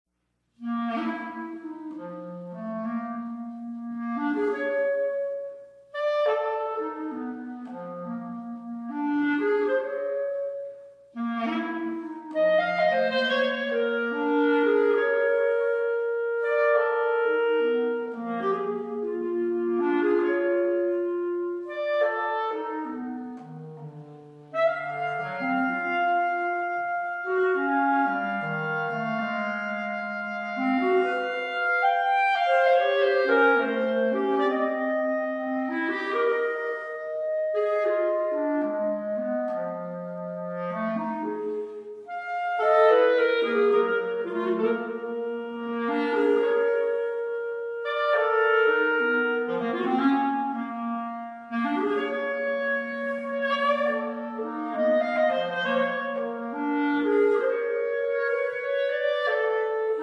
Bb clarinets